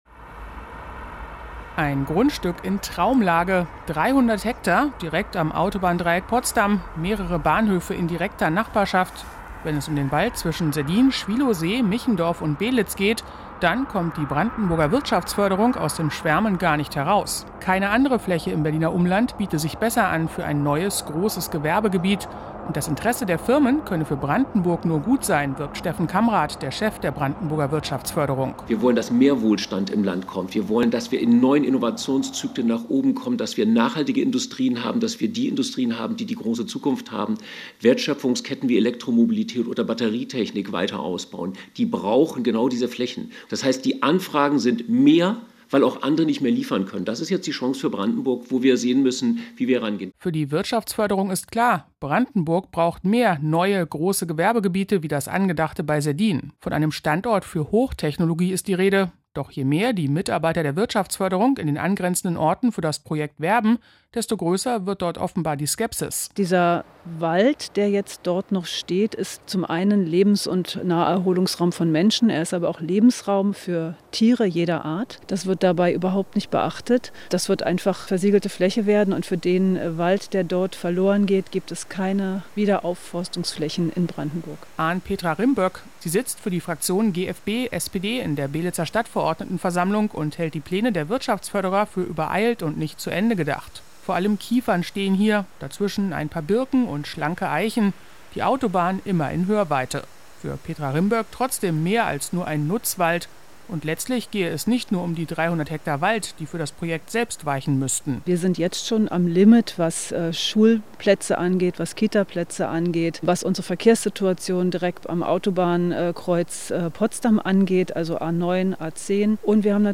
Diese Reportage ist eine Wiederholung.